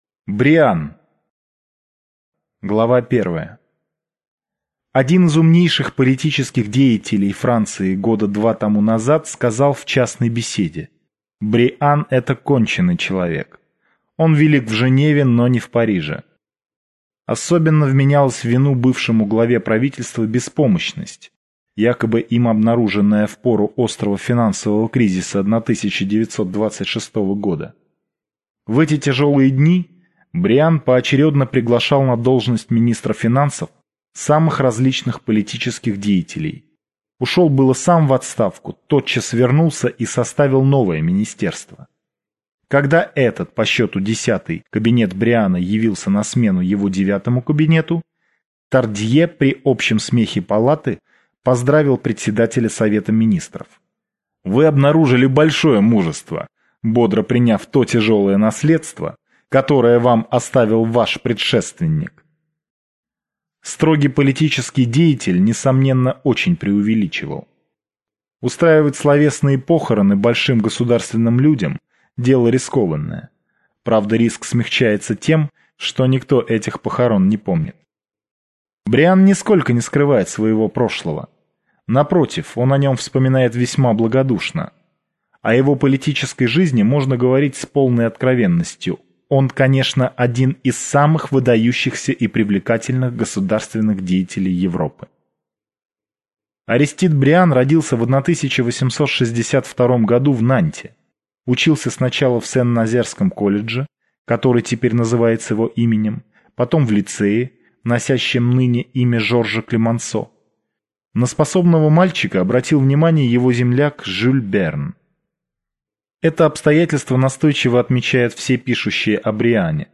Аудиокнига Клемансо. Бриан. Блюм. Прокурор Фукье-Тенвиль | Библиотека аудиокниг